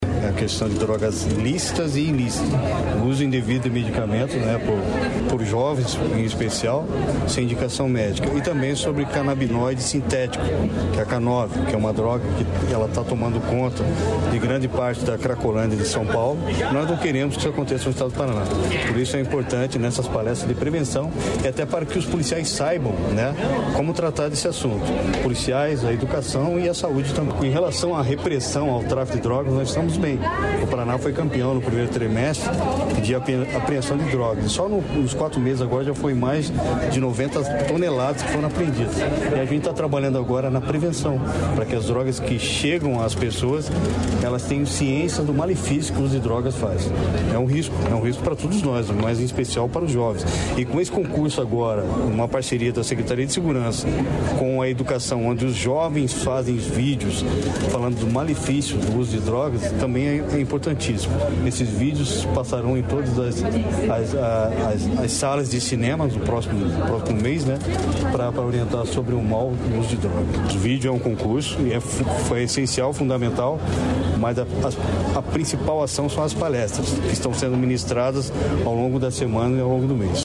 Sonora do secretário da Segurança Pública, Hudson Leôncio Teixeira, sobre o lançamento do Junho Paraná sem Drogas